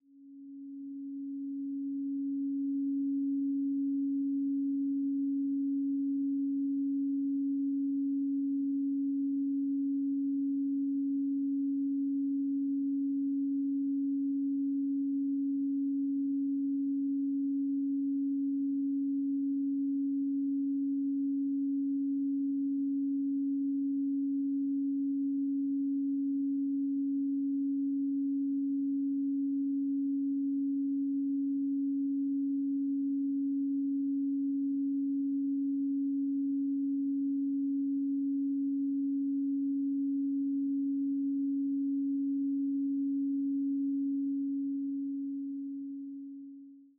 Duration: 0:47 · Genre: Impressionist · 128kbps MP3